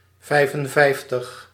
Ääntäminen
Ääntäminen Tuntematon aksentti: IPA: /ˈvɛi̯fənˌvɛi̯ftəx/ Haettu sana löytyi näillä lähdekielillä: hollanti Käännöksiä ei löytynyt valitulle kohdekielelle.